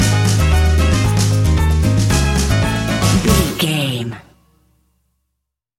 An exotic and colorful piece of Espanic and Latin music.
Aeolian/Minor
C#
maracas
percussion spanish guitar
latin guitar